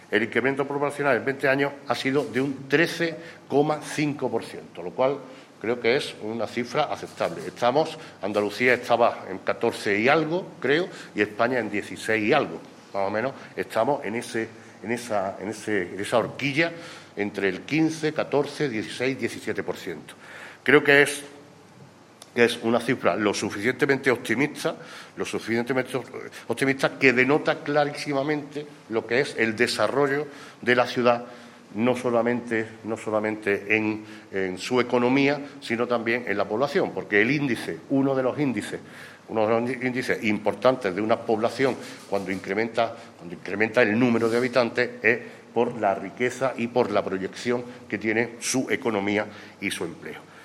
El alcalde de Antequera, Manolo Barón, y el teniente de alcalde delegado de Presidencia y Régimen Interior, Juan Rosas, han informado en rueda de prensa sobre los datos estadísticos del padrón municipal de habitantes de la década que acaba de concluir.
Cortes de voz